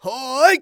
xys蓄力6.wav 0:00.00 0:00.62 xys蓄力6.wav WAV · 53 KB · 單聲道 (1ch) 下载文件 本站所有音效均采用 CC0 授权 ，可免费用于商业与个人项目，无需署名。